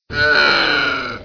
c_camel_hit1.wav